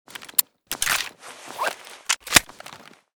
gsh18_reload.ogg.bak